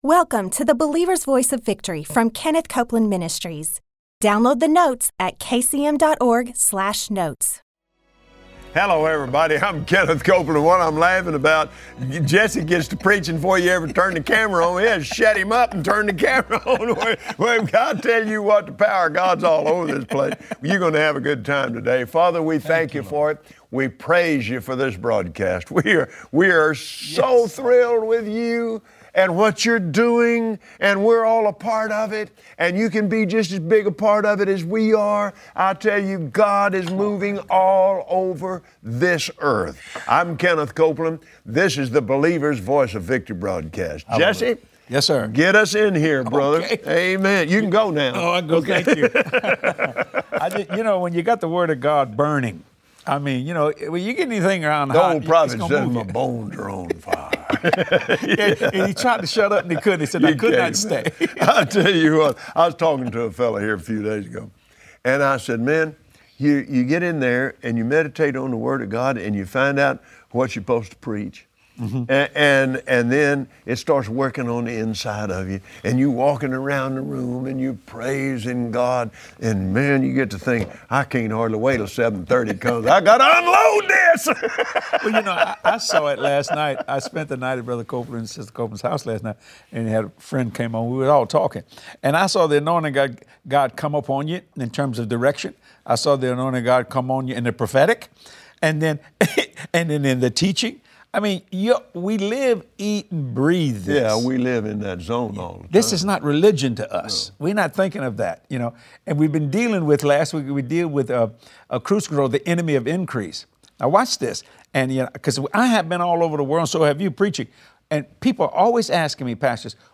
Believers Voice of Victory Audio Broadcast for Tuesday 1/05/2016Today, on the Believer’s Voice of Victory, Kenneth Copeland and his guest, Jesse Duplantis, reveal that the earth and all that is in the earth was given to God’s children.